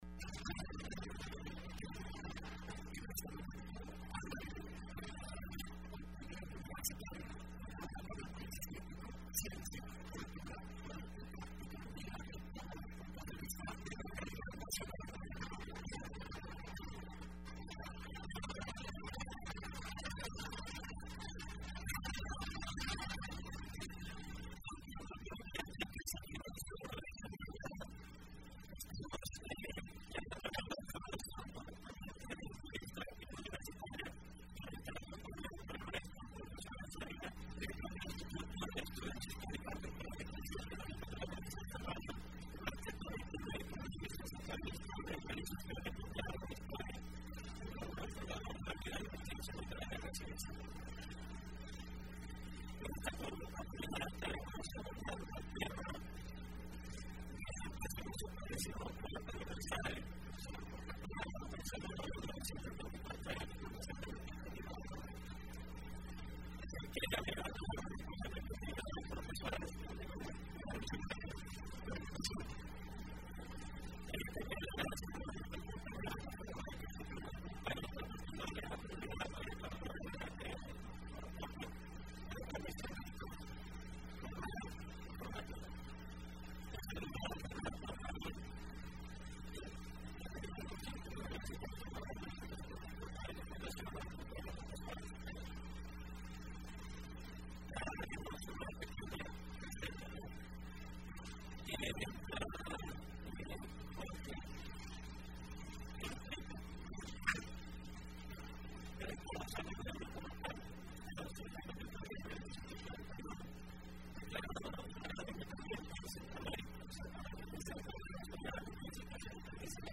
Entrevista Opinión Universitaria (12 febrero 2015): Historia de la Universidad de El Salvador en el marco del 174 aniversario de fundación.